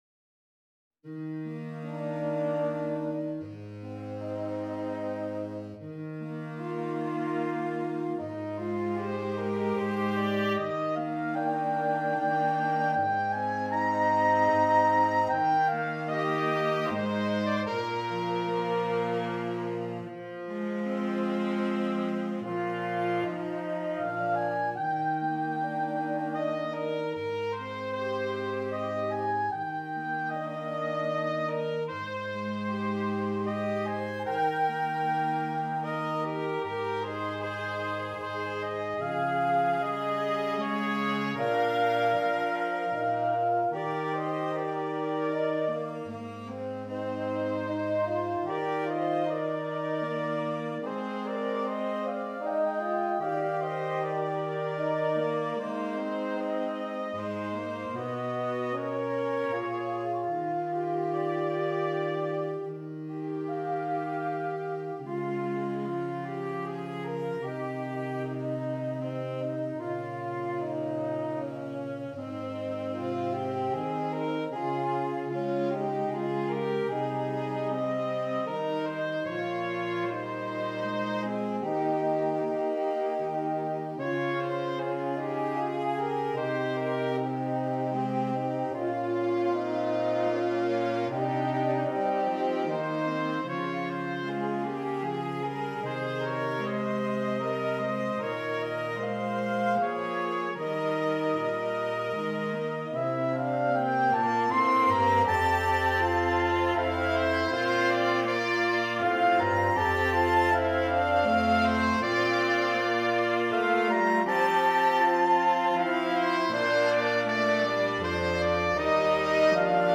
Saxophone Quartet (SATB or AATB)